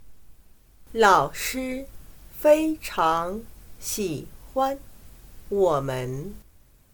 老师非常喜欢我们。/Lǎoshī fēicháng xǐhuān wǒmen./El profesor nos quiere mucho.